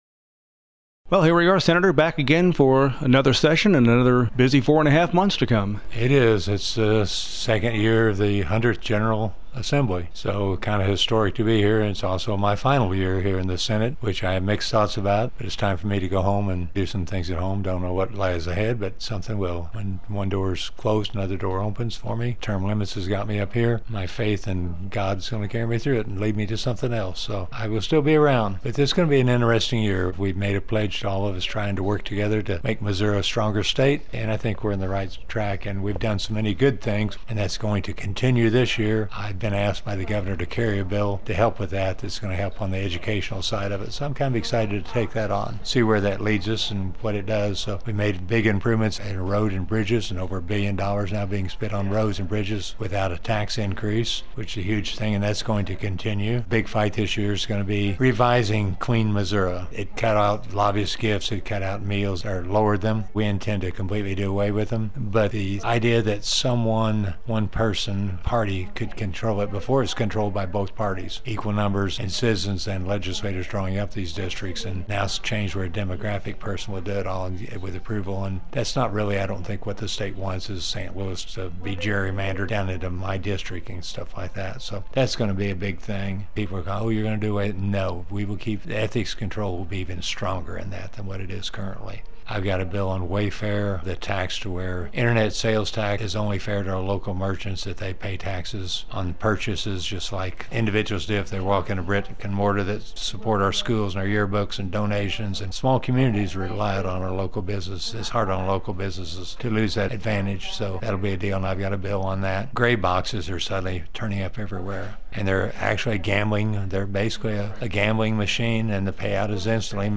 JEFFERSON CITY — State Sen. Mike Cunningham, R-Rogersville, discusses start of the 2020 legislative session and some of his priorities for the year.